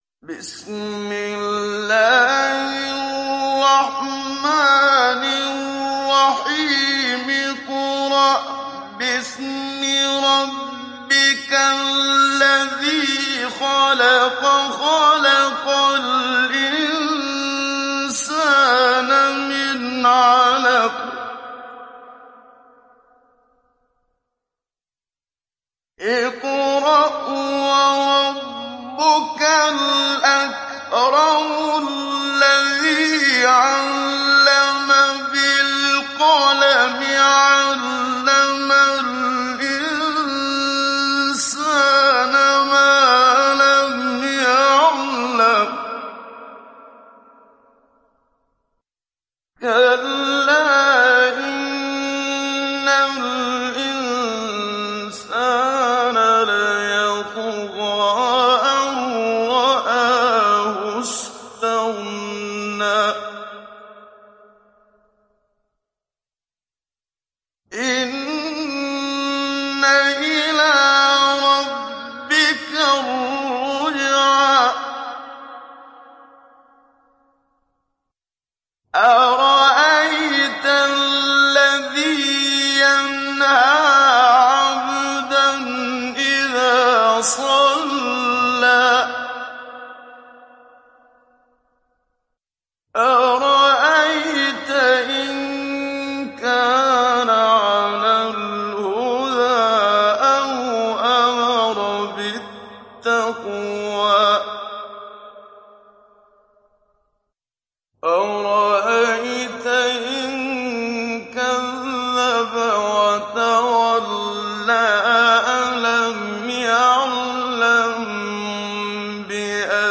تحميل سورة العلق mp3 بصوت محمد صديق المنشاوي مجود برواية حفص عن عاصم, تحميل استماع القرآن الكريم على الجوال mp3 كاملا بروابط مباشرة وسريعة
تحميل سورة العلق محمد صديق المنشاوي مجود